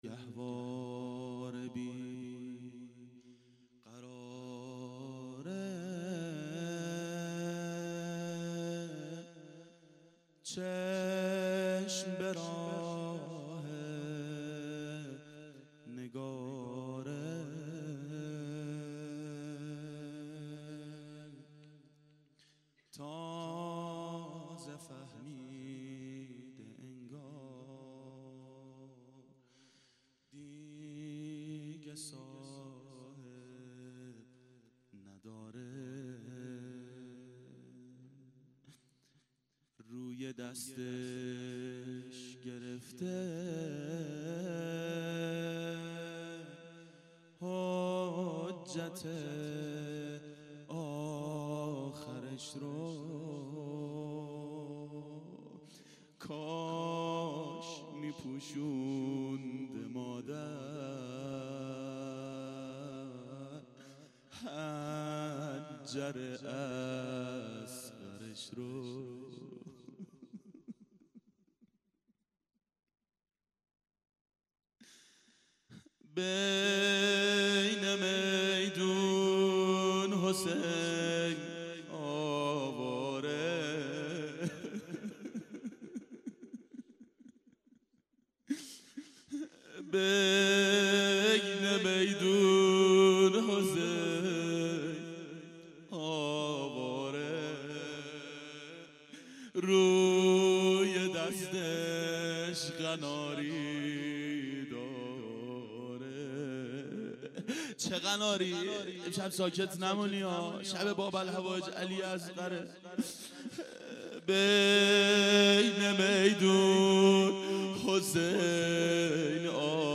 روضه حضرت علی اصغر علیه السلام
دهه اول صفر سال 1392 هیئت شیفتگان حضرت رقیه سلام الله علیها
01-روضه-علی-اصغر.mp3